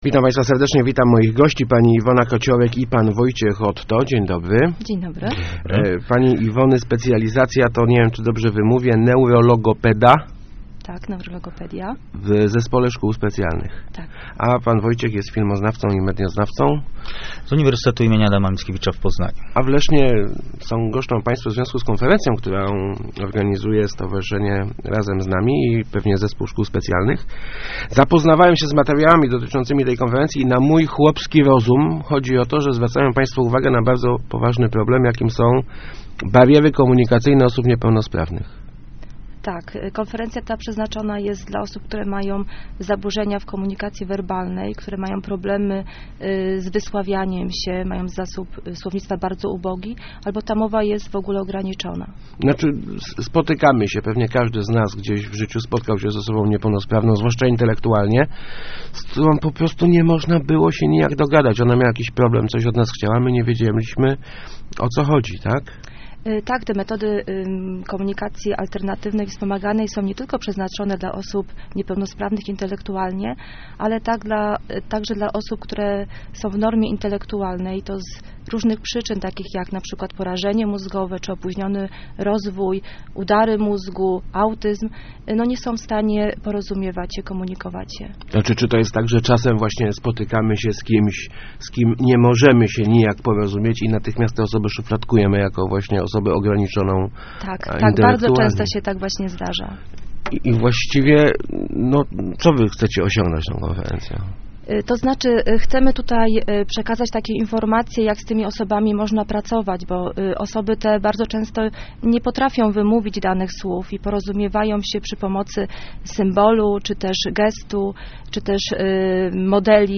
Start arrow Rozmowy Elki arrow Niepełnosprawni za barierą ciszy